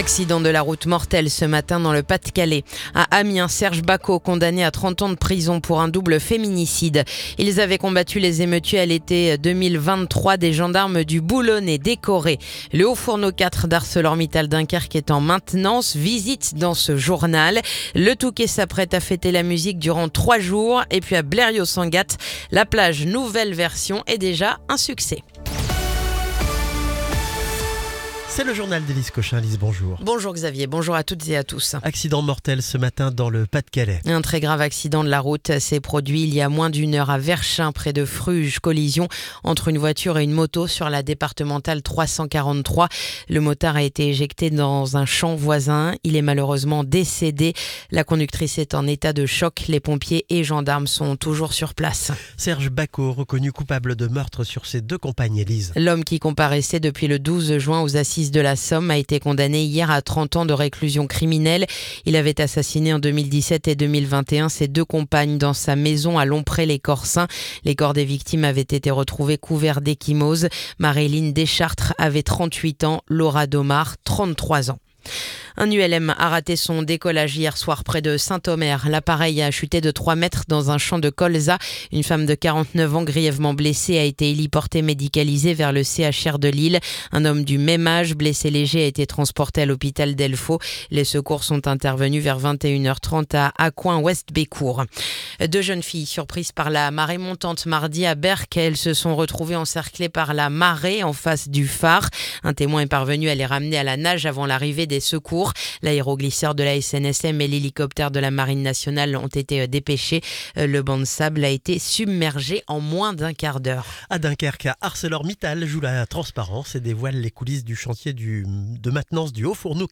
Le journal du jeudi 19 juin